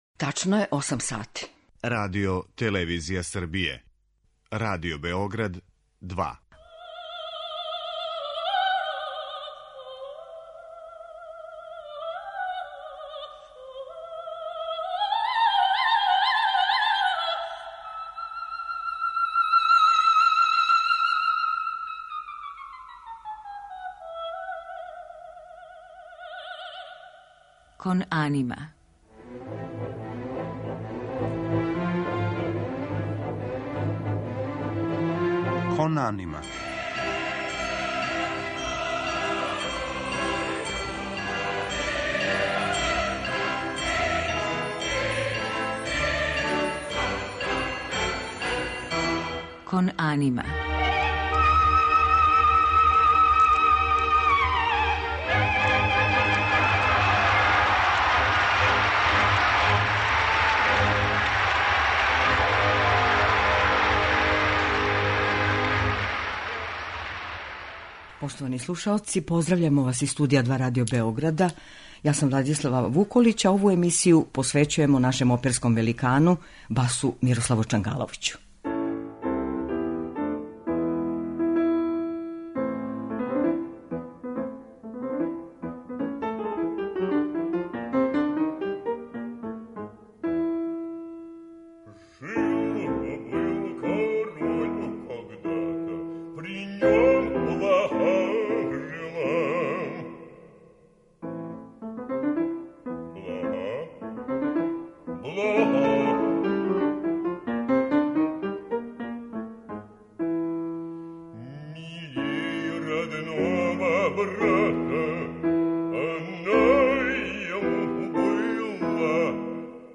У музичком делу биће емотоване арије и сцене из најпознатијих опера, у којима је славни бас остварио гласом и глумом роле за памћење, посебно у "Борису Годунову", Модеста Мусоргског.